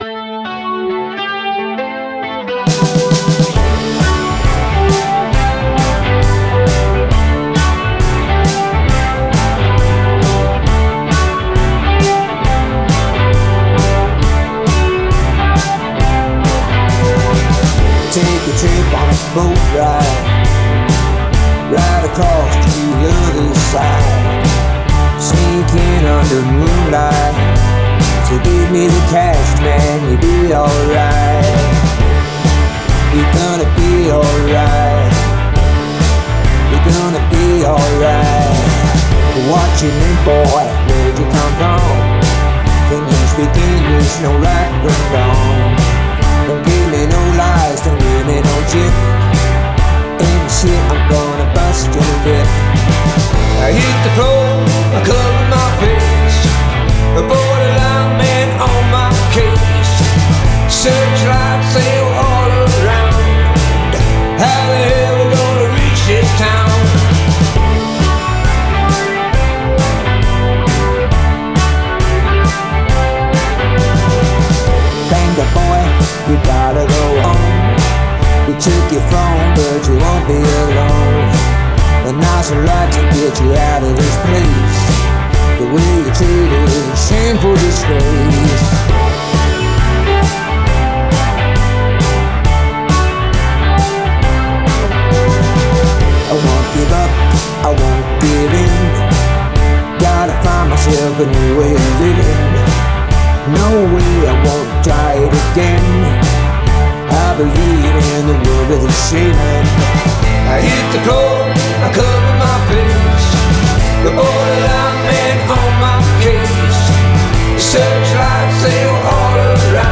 ich würde hier gerne nochmal eine 2. version vom barricade v3 limiter nachreichen, da die erste offensichtlich zu stark nach unten komprimiert wurde.